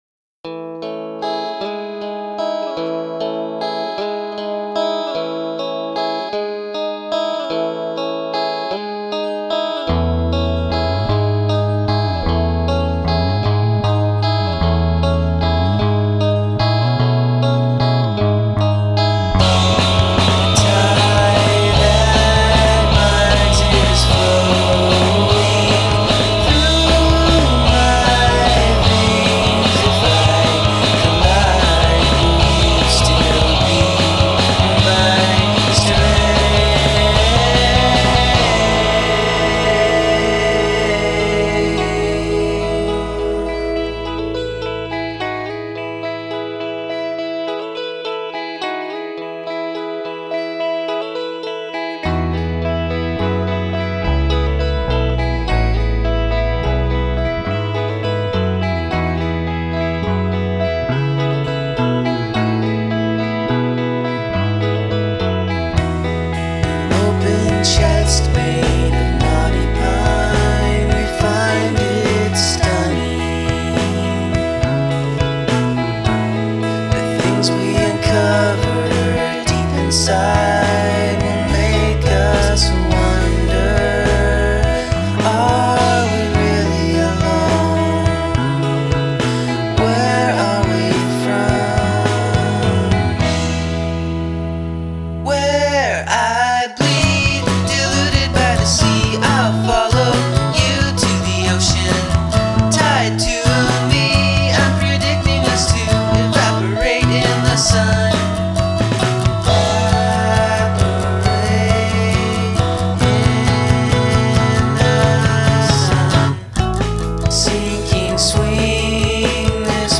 Must include three different tempos